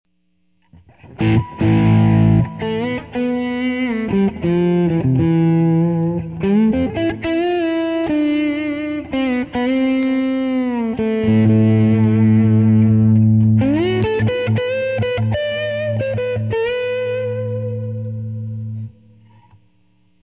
HG Thor Epoxy Fretless Guitar Example:
This is an Epiphone Casino guitar with original binding that has had the frets removed, maple inlays installed and HG Thor Epoxy coating applied.